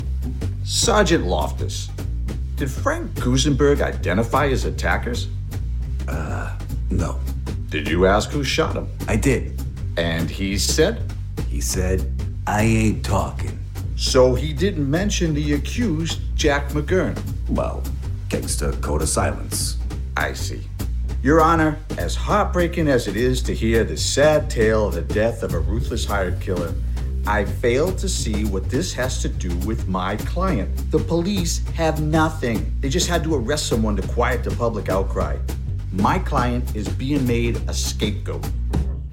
Middle Aged
My voice has been described as warm, trustworthy, and confident.